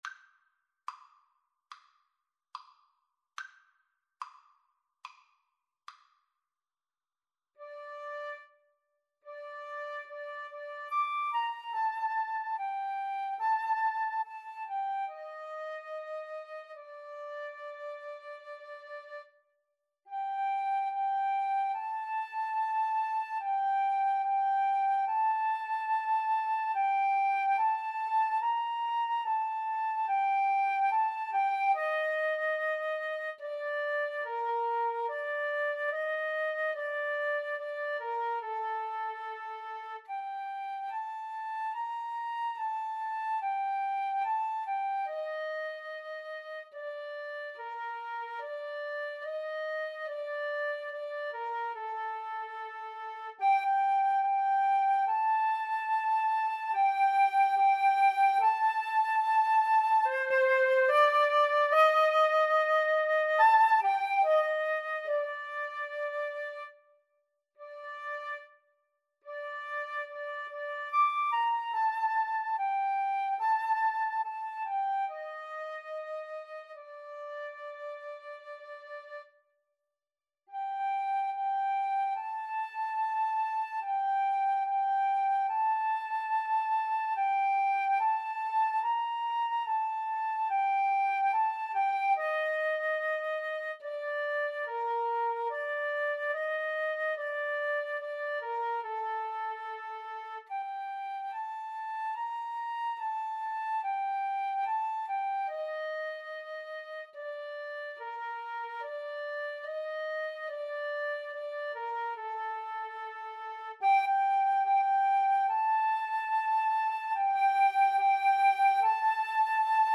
Traditional Music of unknown author.
Semplice = c.72
4/4 (View more 4/4 Music)
World (View more World Flute-Clarinet Duet Music)